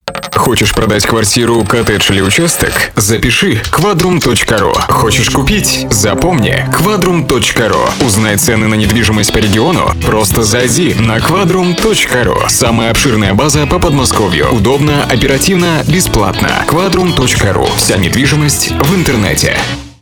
Информационный радиоролик компании "Квадрум" Категория: Аудио/видео монтаж
Разработка и запись рекламного ролика для компании "Квадрум".